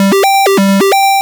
retro_synth_beeps_04.wav